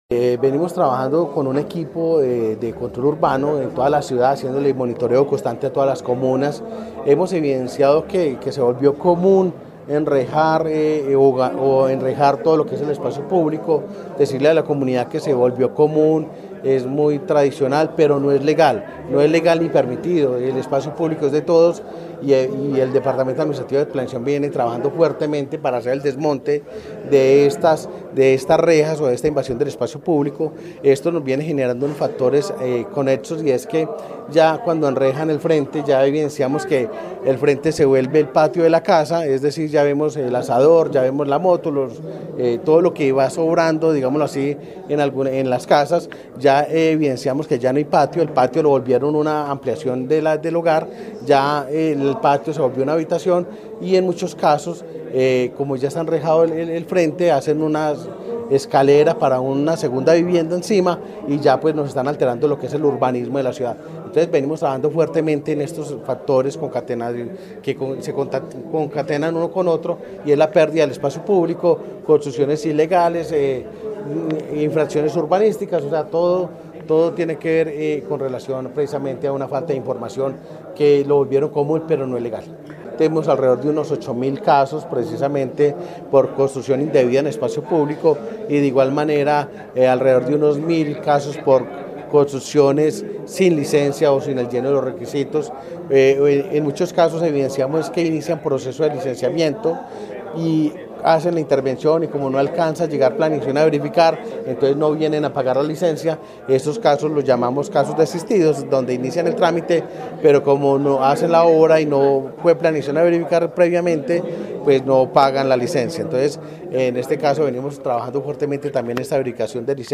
Director del departamento administrativo de planeación de Armenia